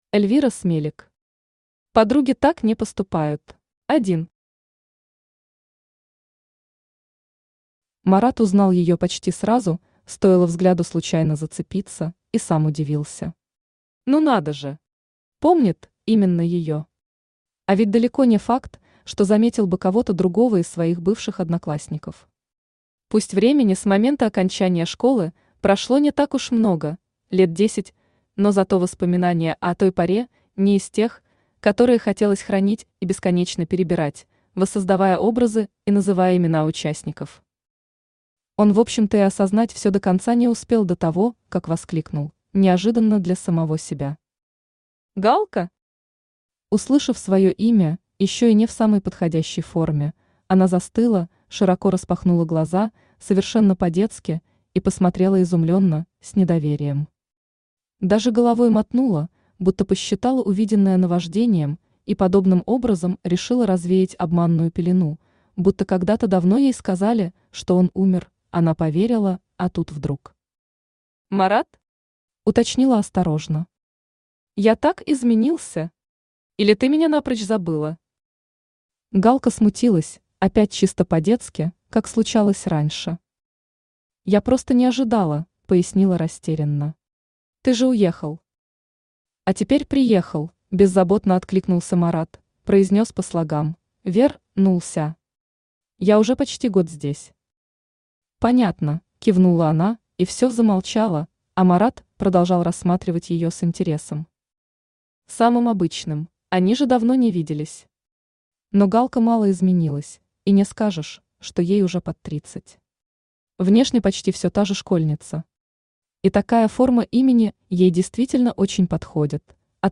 Аудиокнига Подруги так не поступают | Библиотека аудиокниг
Aудиокнига Подруги так не поступают Автор Эльвира Смелик Читает аудиокнигу Авточтец ЛитРес.